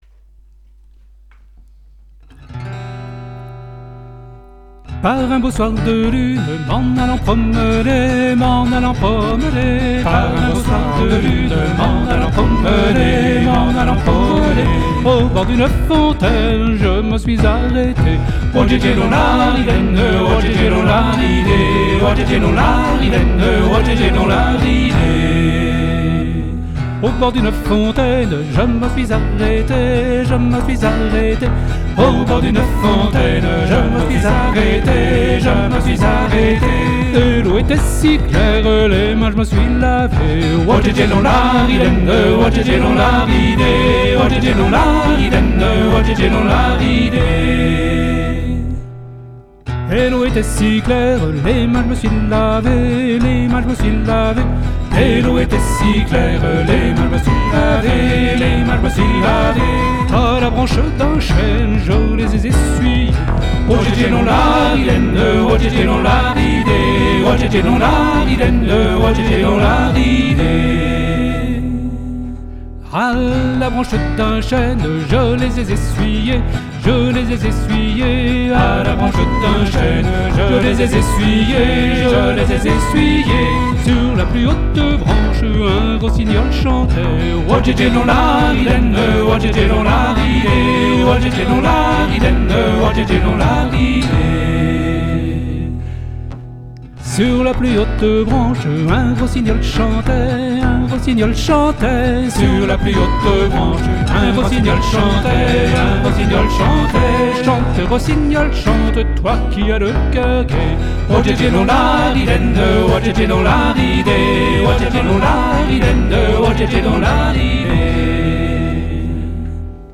Traditionnel Bretagne